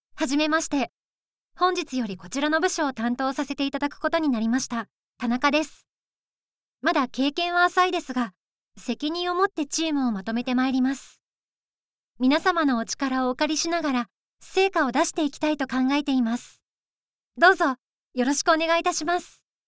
優しく、誠実な声が特徴です。
新米社会人
female05_46.mp3